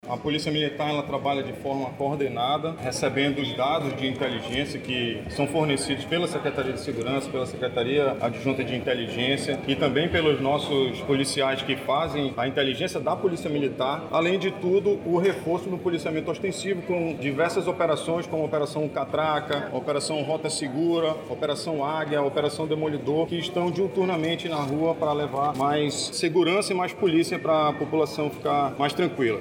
O comandante-geral da Polícia Militar do Amazonas – PMAM, coronel Klinger Paiva, explica que outra estratégia para o combate aos crimes são as operações policiais.